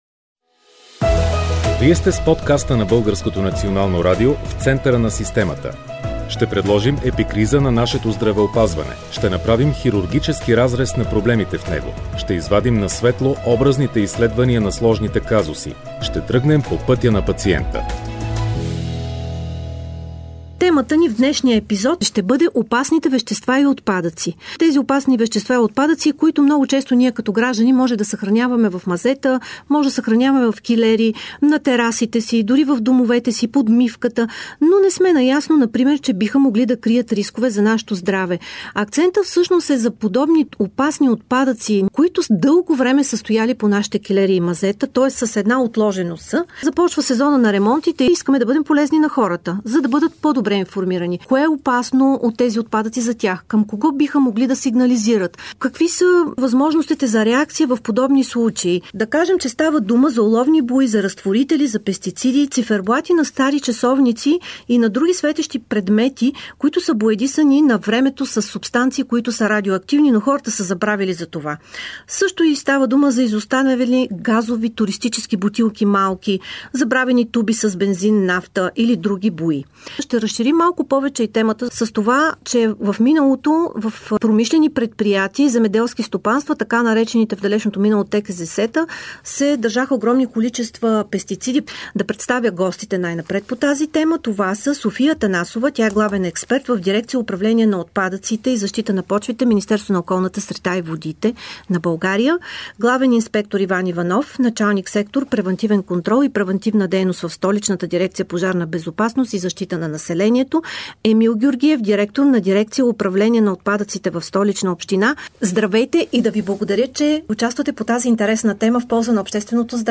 В този епизод експерти от Околната среда, Столичната община и Столичната пожарна разказват за Опасни вещества и токсични отпадъци